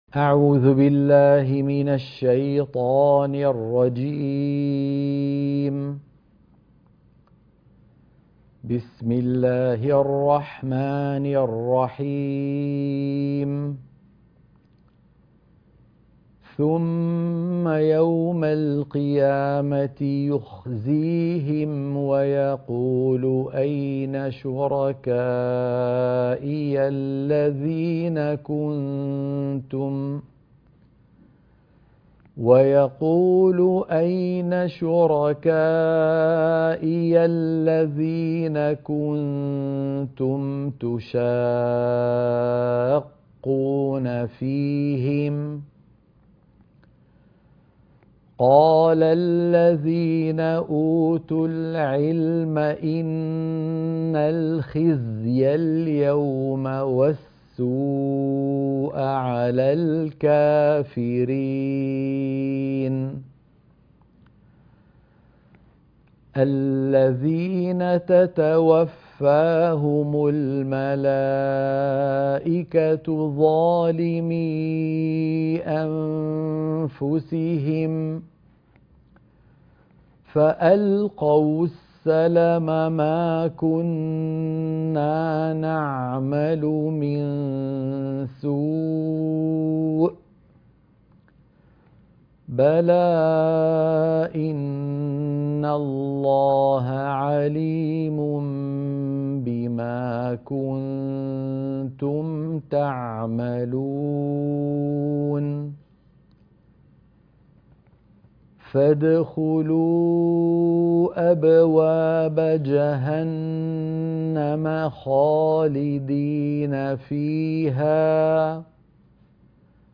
تلاوة نموذجية للآيات 27 - 34 من سورة النحل